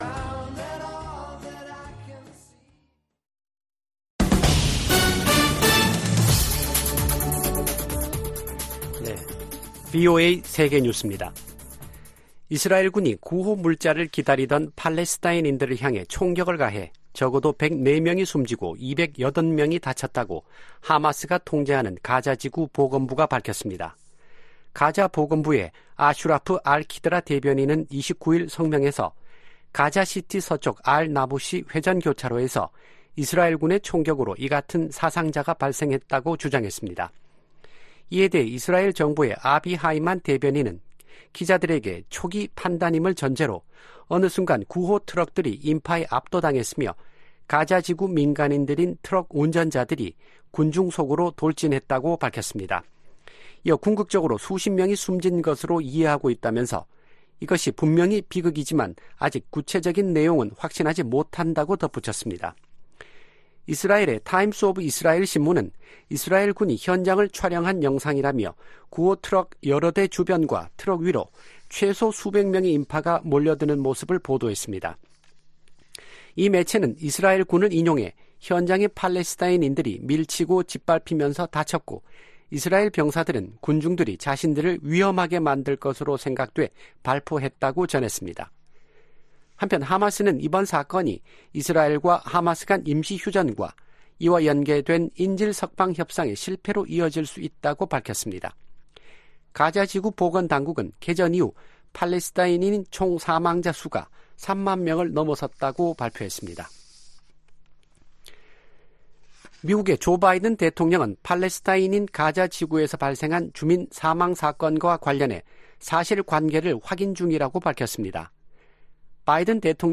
VOA 한국어 아침 뉴스 프로그램 '워싱턴 뉴스 광장' 2024년 3월 1일 방송입니다. 미국과 한국의 외교장관들이 워싱턴 D.C.에서 만나 세계의 거의 모든 도전에 공조하는 등 양국 협력이 어느 때보다 강력하다고 평가했습니다. 북한이 유엔 군축회의에서 국방력 강화 조치는 자위권 차원이라며 비난의 화살을 미국과 동맹에게 돌렸습니다. 세계 최대 식품 유통업체가 북한 강제 노동 동원 의혹을 받고 있는 중국 수산물 가공 업체 거래를 전격 중단했습니다.